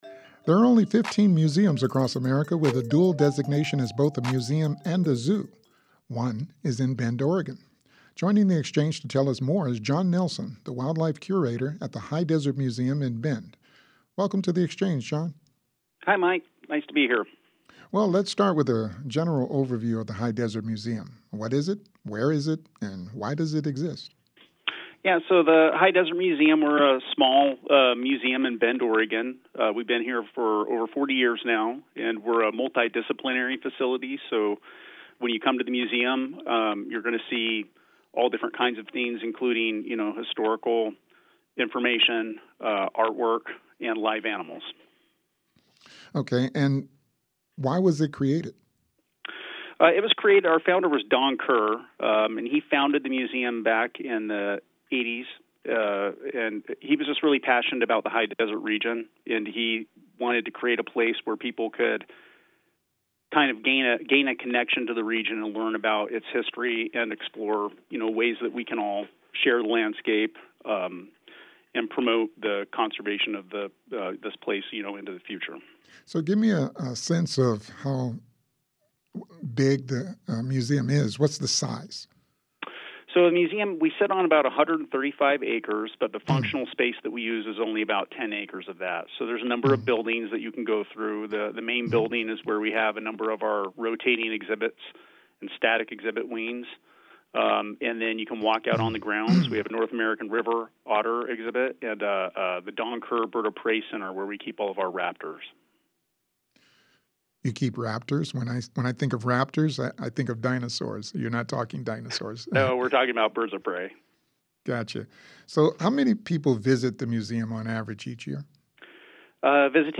JPR's live interactive program devoted to current events and newsmakers from around the region and beyond._______________________________________________________________________________Subscribe to access all episodes: